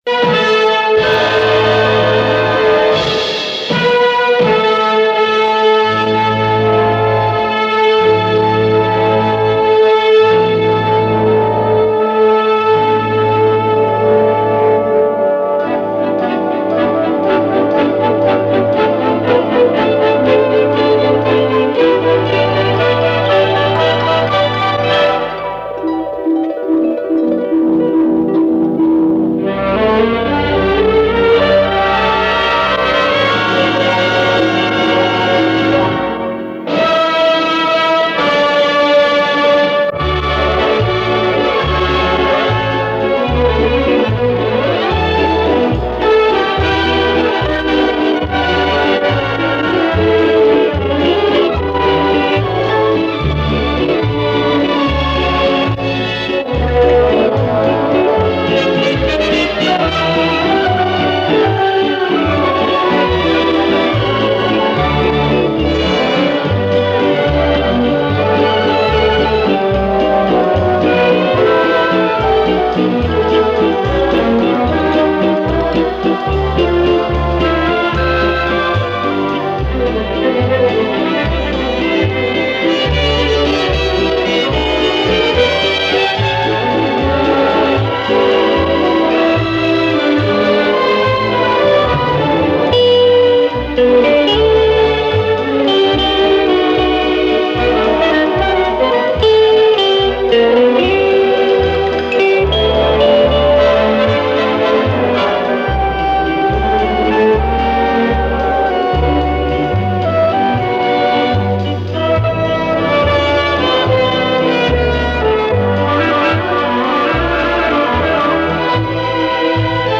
Неизвестный вальс
Часто звучал в советском радиоэфире
116.orkestr---vals-(neopr.).mp3